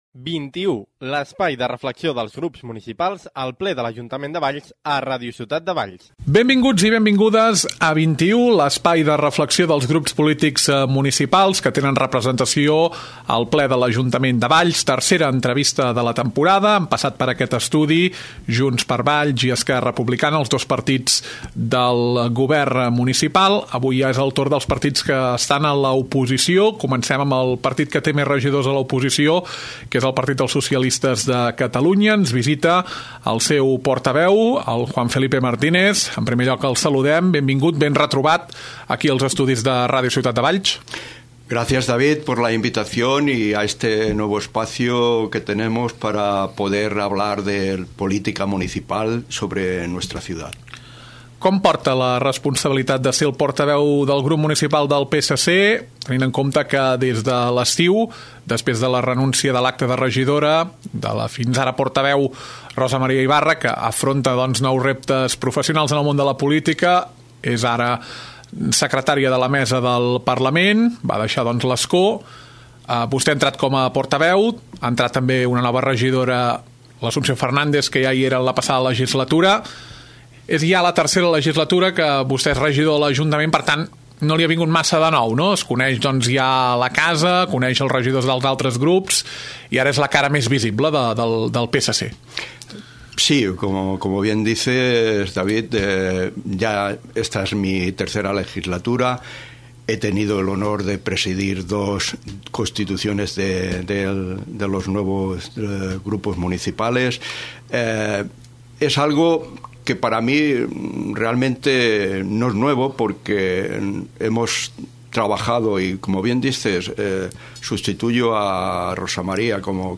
Nova temporada de 21, l’espai de reflexió dels grups municipals que tenen representació al ple de l’Ajuntament de Valls. Ronda d’entrevistes als diferents portaveus de major a menor proporció a la sala de plens. Avui és el torn de Juan Martínez del PSC.